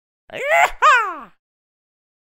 Звуки ковбоев
Звук клика ковбоя ииихааа